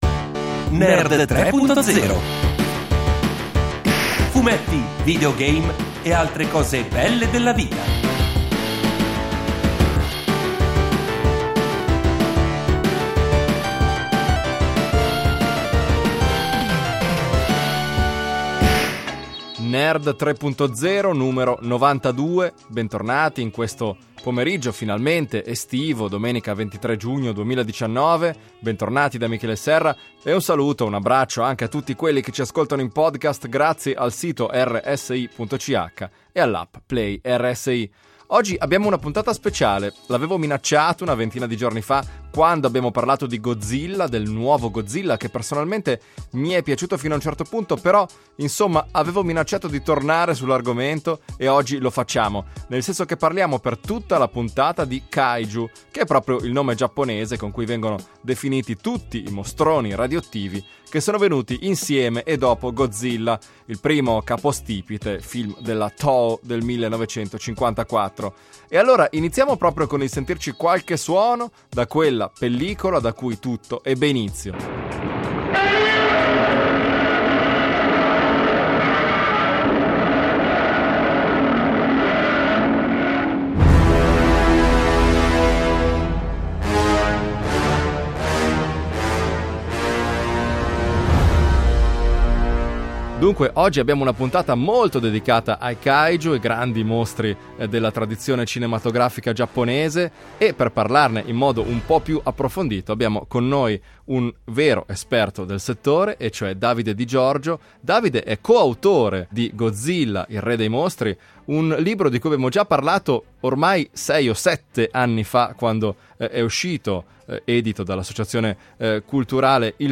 Una chiacchierata